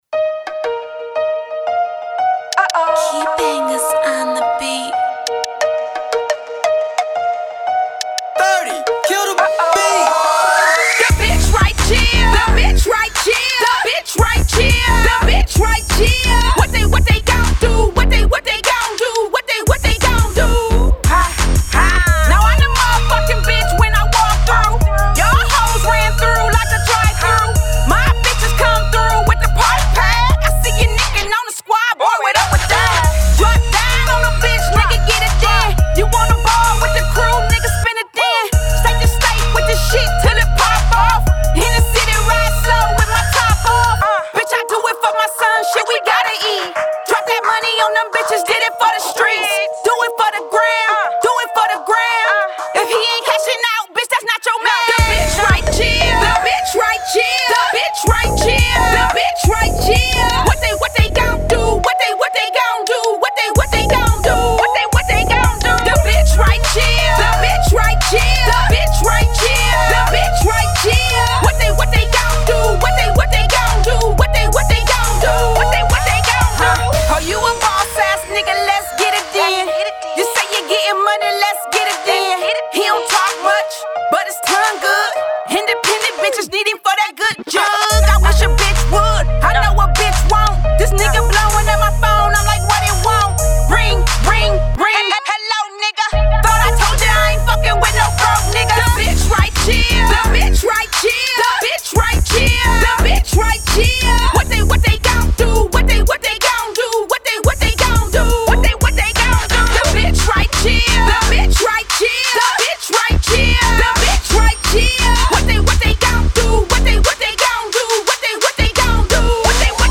Hiphop
Female spin off